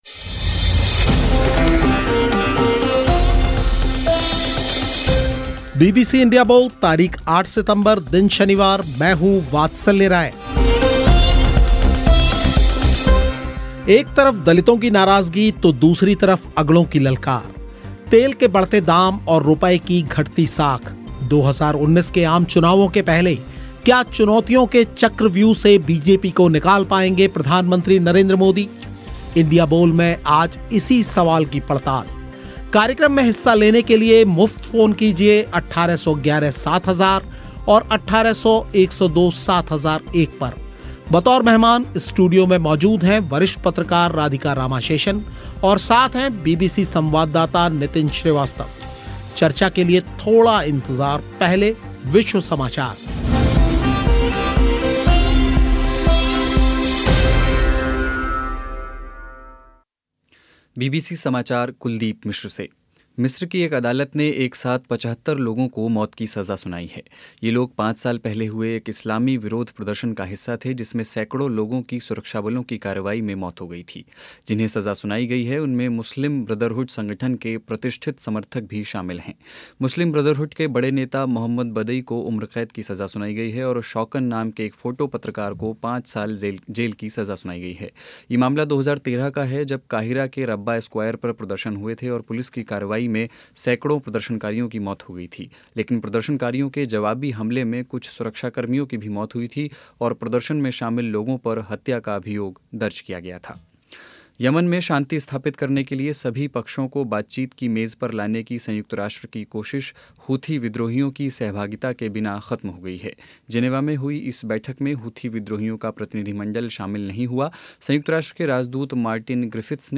Headliner Embed Embed code See more options Share Facebook X Subscribe एक तरफ दलितों की नाराज़गी, तो दूसरी तरफ अगड़ों की ललकार तेल के बढ़ते दाम और रुपये की घटती साख 2019 के आम चुनावों के पहले क्या चुनौतियों के चक्रव्यूह से बीजेपी को निकाल पाएँगे प्रधानमंत्री नरेंद्र मोदी? इंडिया बोल इसी सवाल पर हुई चर्चा बतौर मेहमान स्टूडियो में मौजूद रहीं वरिष्ठ पत्रकार
और श्रोताओं ने भी हिस्सा लिया